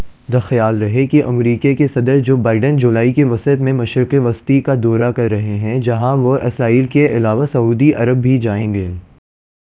Spoofed_TTS/Speaker_14/267.wav · CSALT/deepfake_detection_dataset_urdu at main